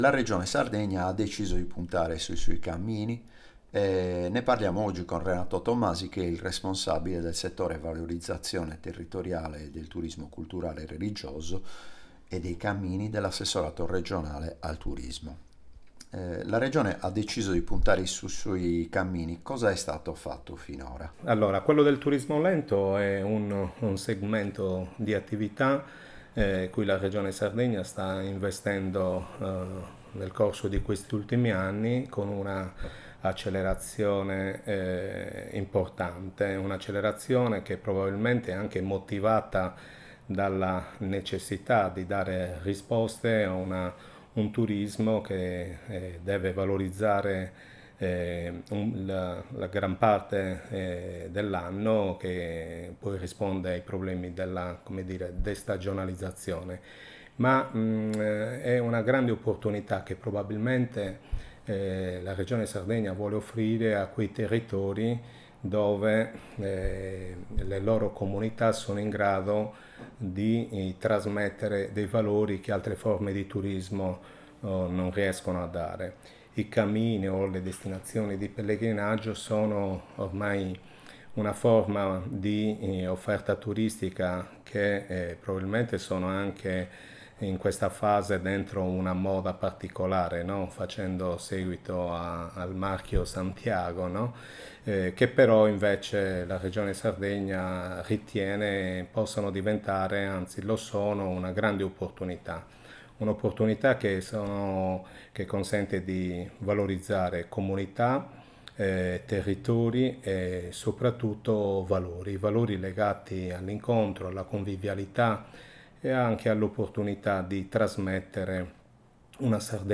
audio intervista